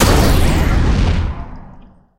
robotwin_explo_01.ogg